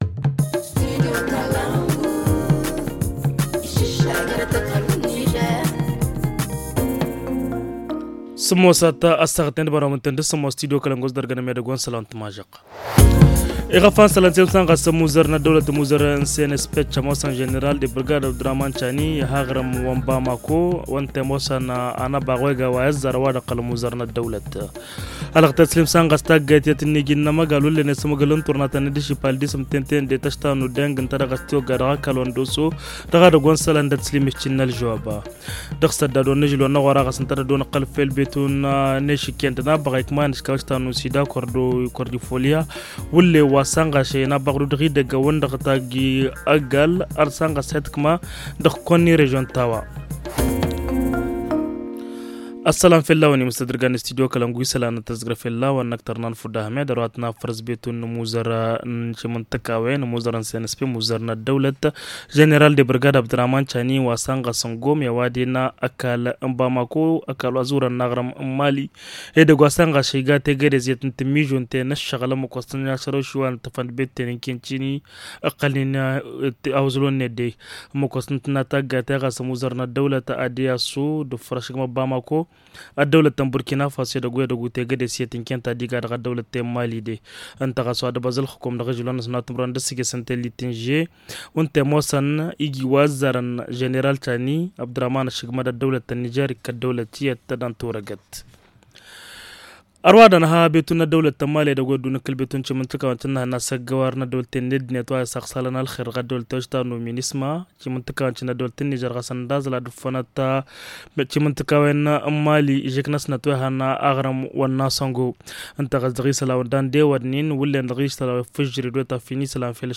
Le journal du 23 novembre 2023 - Studio Kalangou - Au rythme du Niger